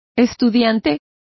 Complete with pronunciation of the translation of schoolboys.